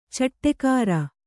♪ caṭṭekāra